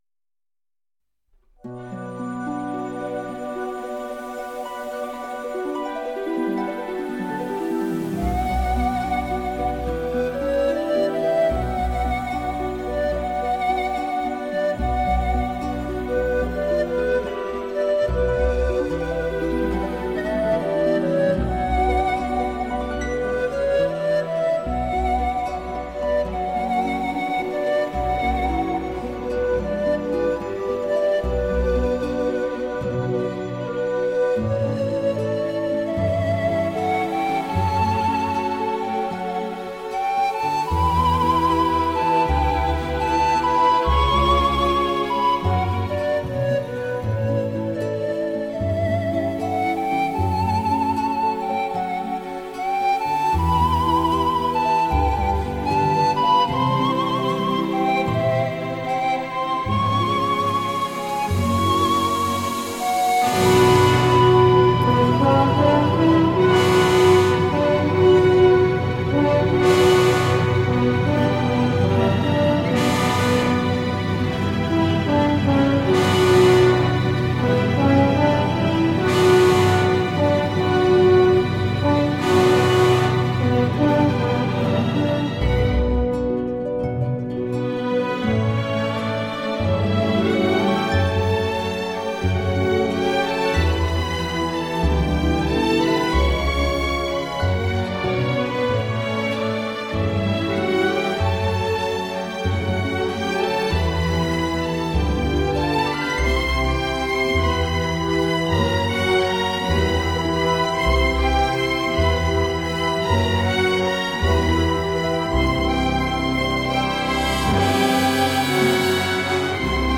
最优美轻音乐，让心灵完全无法抵御的浪漫醉人仙音！
轻盈空灵—缔造轻音乐完美聆听主义，心灵养生—情怀触动令人蚀骨销魂。
响彻世界的优美高雅之乐，无法抵御的浪漫心灵之声，无法抵御的声色灵魂之境，自然超脱尤如世外仙音。